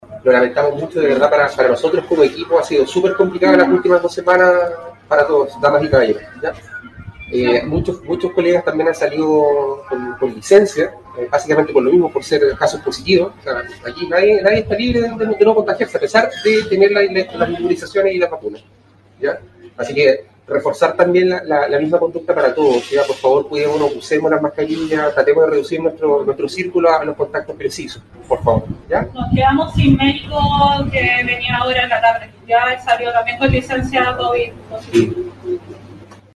Otro de los funcionarios solicitó extremar las medidas de autocuidado, ya que las últimas dos semanas han sido críticas en el combate de la pandemia.